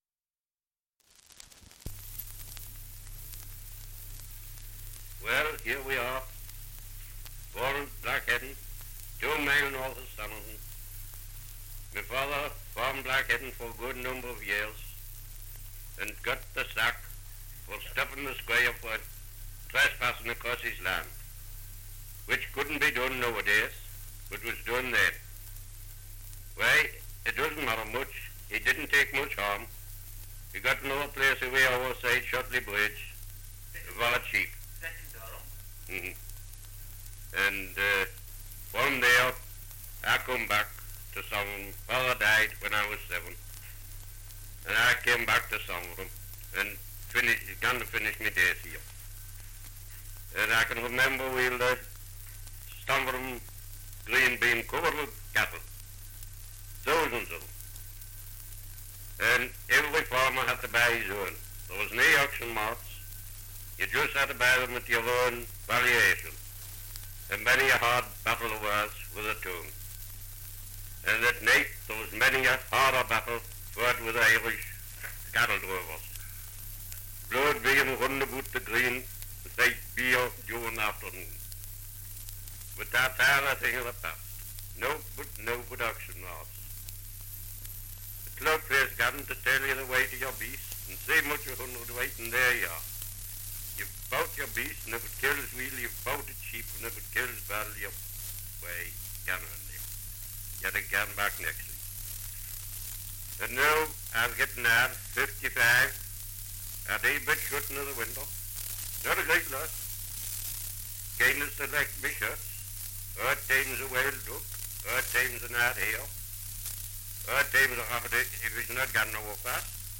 Dialect recording in Stamfordham, Northumberland
78 r.p.m., cellulose nitrate on aluminium